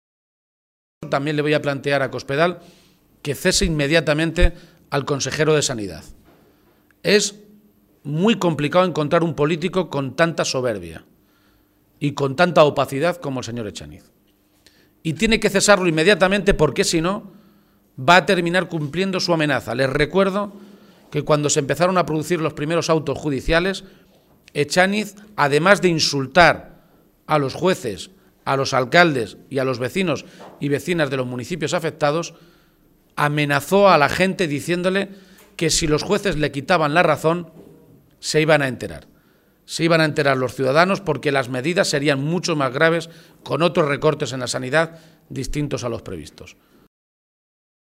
Emiliano García-Page durante la rueda de prensa celebrada en Talavera
Cortes de audio de la rueda de prensa